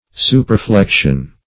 Search Result for " superreflection" : The Collaborative International Dictionary of English v.0.48: Superreflection \Su`per*re*flec"tion\, n. The reflection of a reflected image or sound.
superreflection.mp3